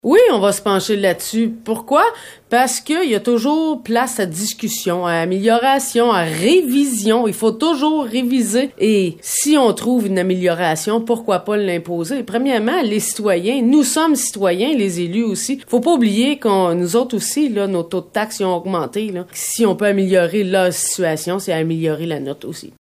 Durant la séance, un citoyen a fait la demande formelle au conseil municipal d’abaisser le taux de taxes. Voici la réponse de la mairesse :